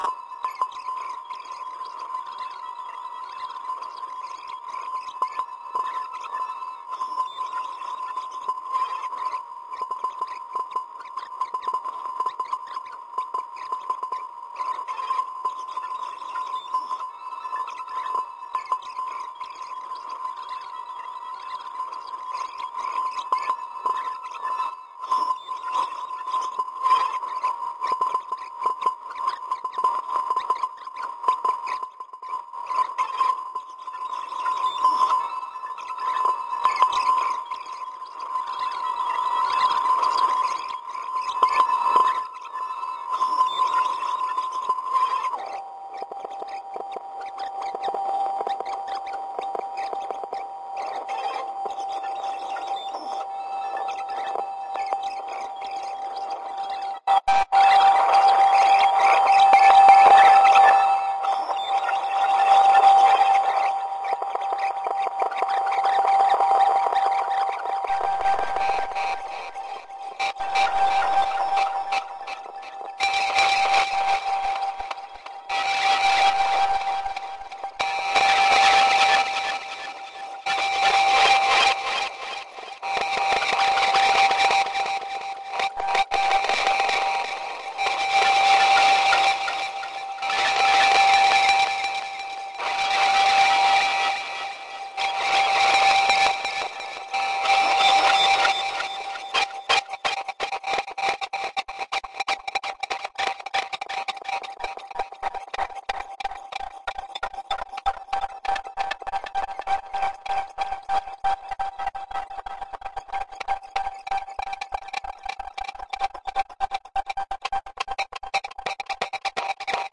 描述：诡异的共鸣电脑在几分钟内演变2分钟后可能会形成节奏或者是吸多了大麻？
标签： 盖革 处理 计算机 共鸣
声道立体声